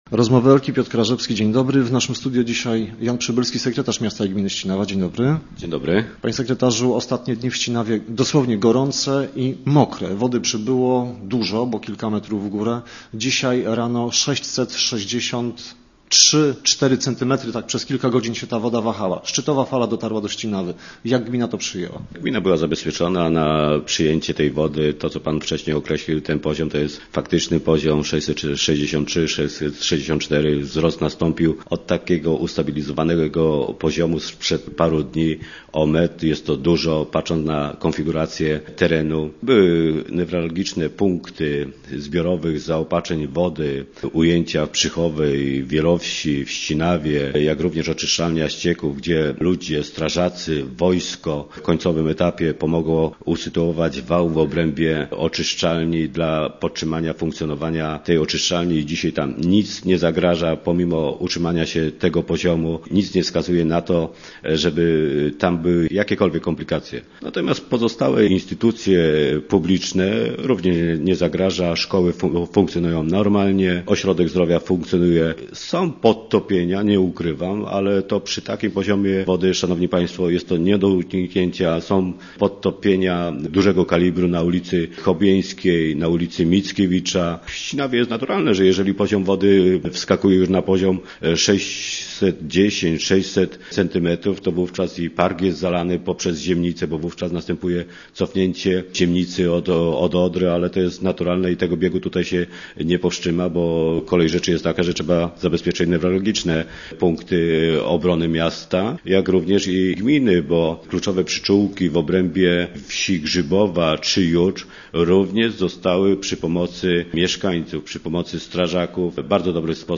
Dzisiejszy gość Rozmów Elki. Informuje jednak, że fala kulminacyjna może utrzymać się na wysokim poziomie nawet przez 3-4 dni, a to może być „zabójcze” dla wałów chroniących tereny wiejskie.